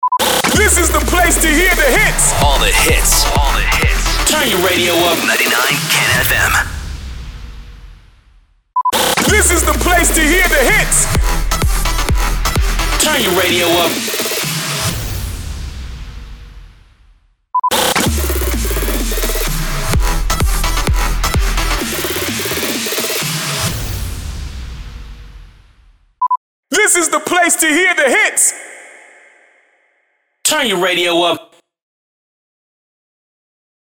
307 – SWEEPER – THE PLACE TO HEAR THE HITS